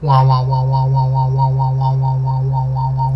snd_player_deathbomb.wav